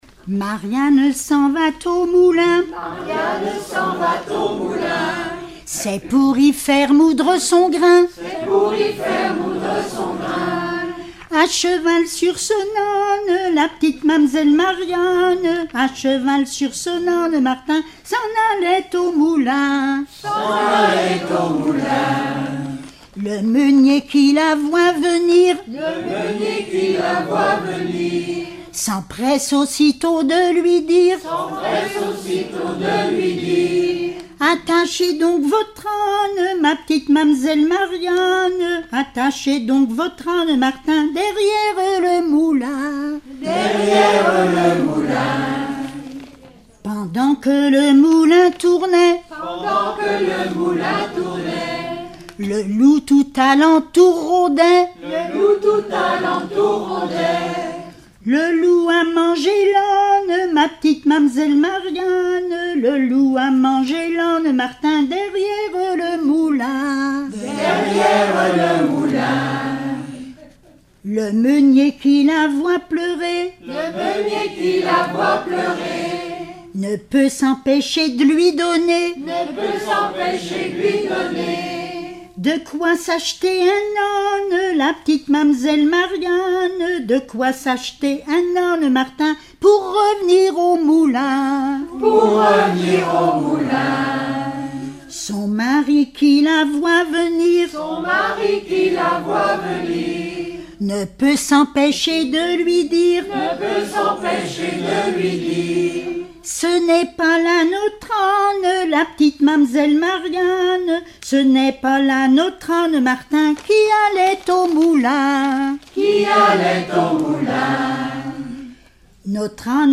Genre strophique
Répertoire de chansons populaires et traditionnelles